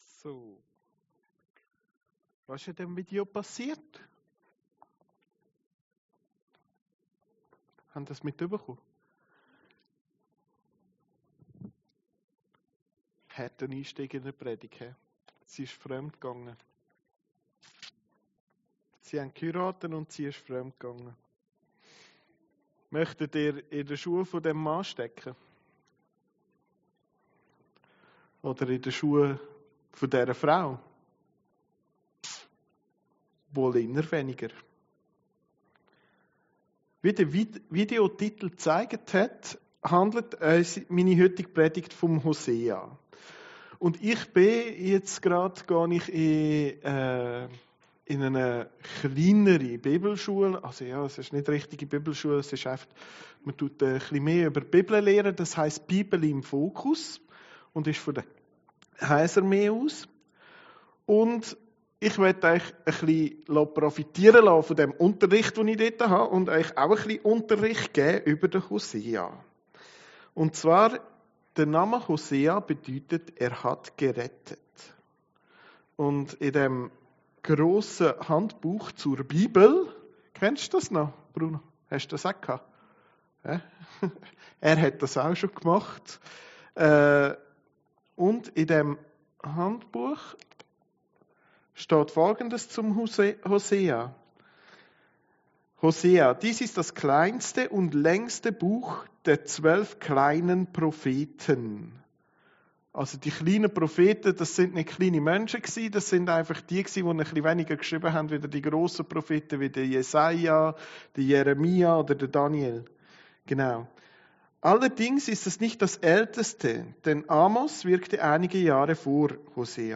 Predigten Heilsarmee Aargau Süd – HOSEA